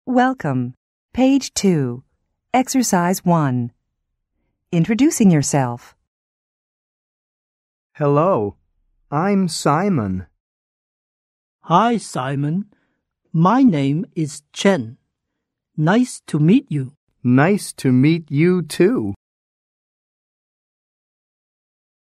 American English